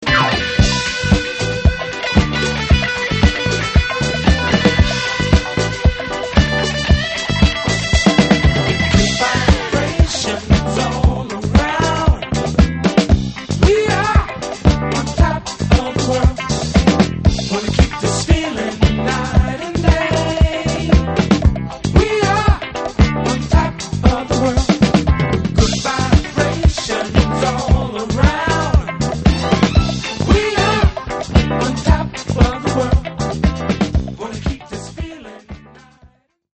メロウでスィートな
ファンク・チューンに挟みこまれたフリー・ソウル／ＡＯＲな楽曲がたまらない。